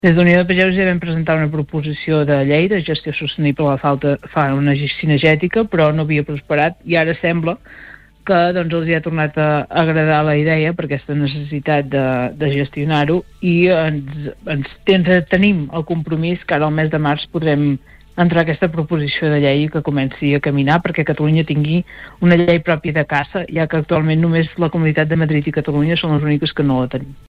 En una entrevista al Supermatí